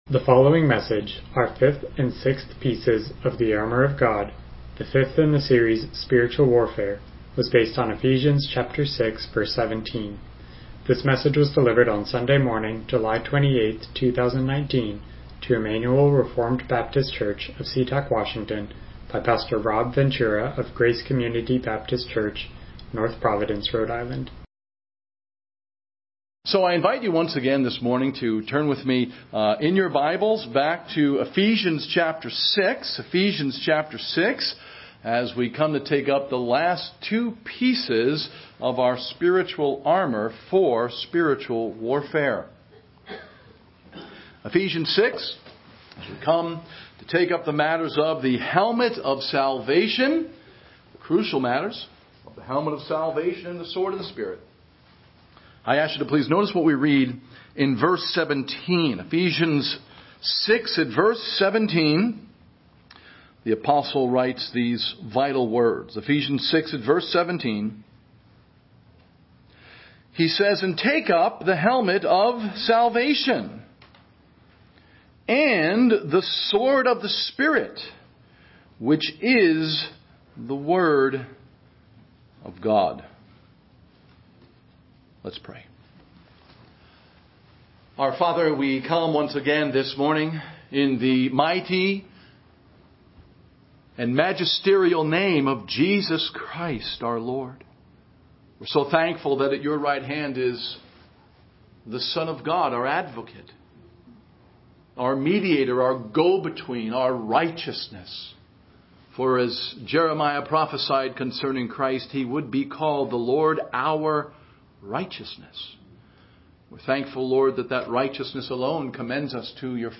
Passage: Ephesians 6:17 Service Type: Morning Worship